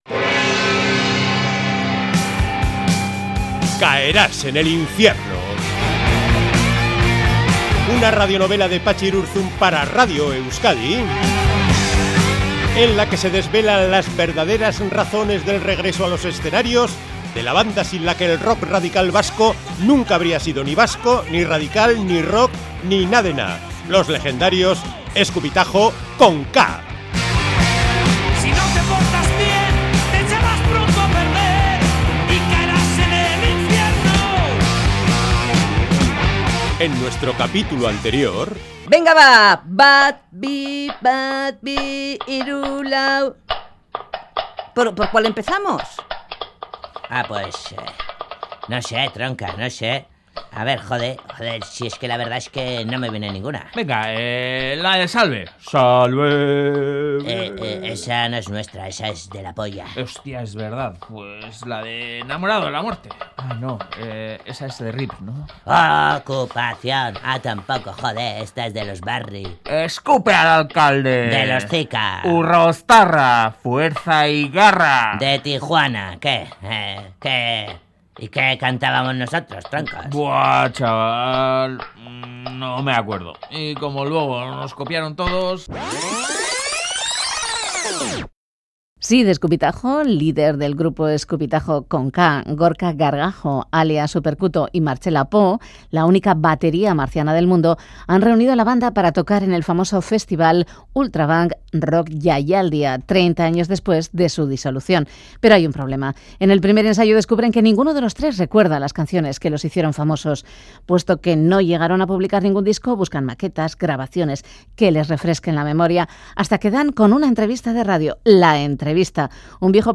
Radio novela